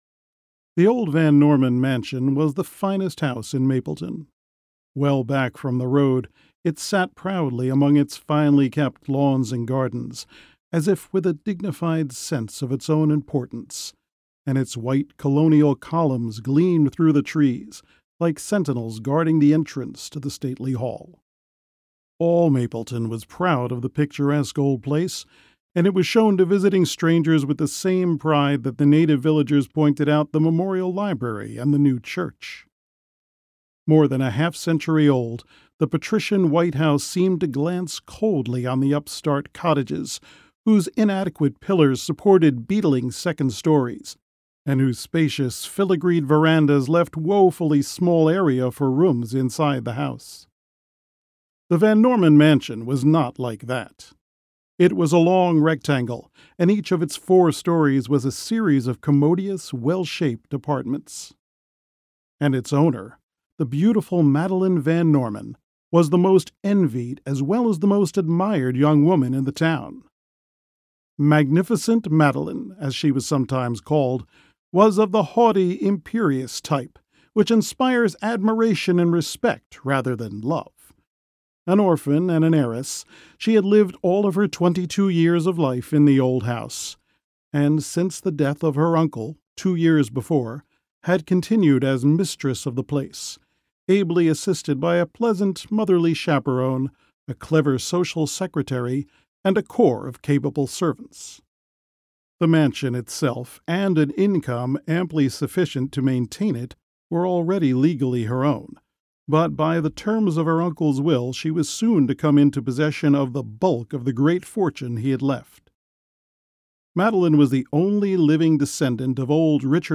Narrator: